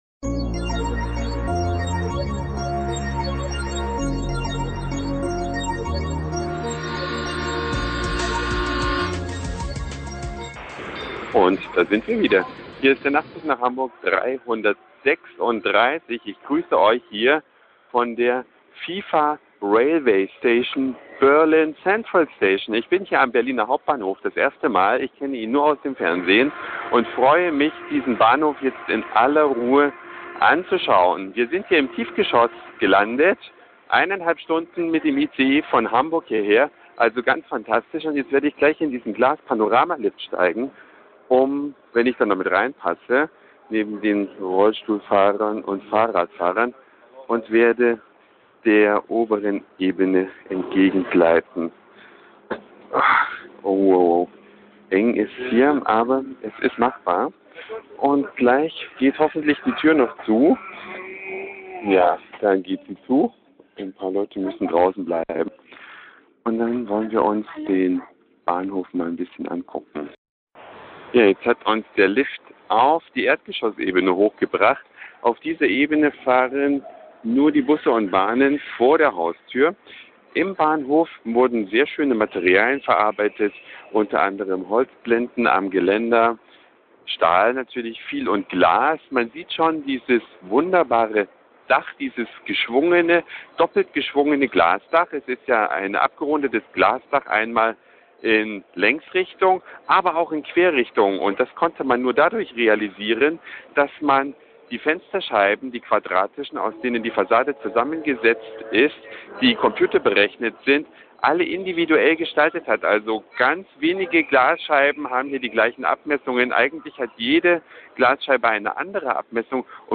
Mit dem ICE nach Berlin, Ausstieg am neuen Hauptbahnhof.